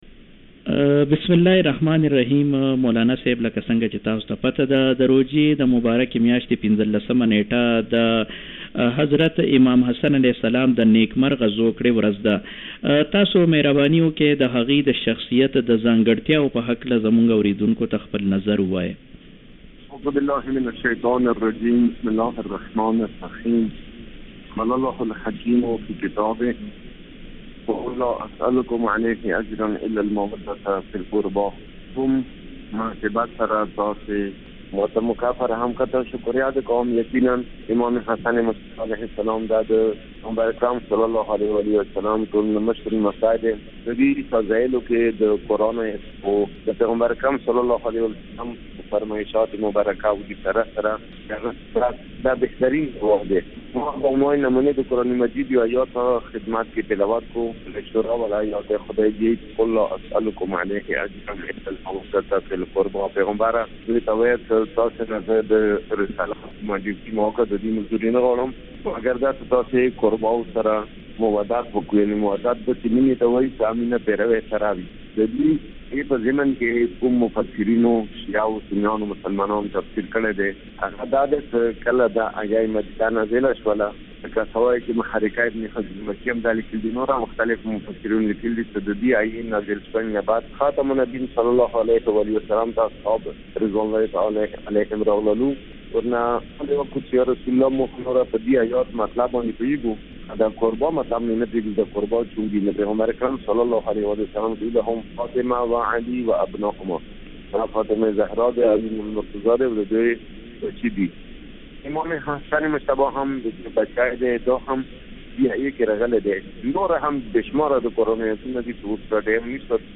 د حضرت امام حسن مجتبی (ع)نیکمرغه زوکړه دې ټولو ته مبارک وي(مرکه)
د تهران ریډیو پښتو خپرونې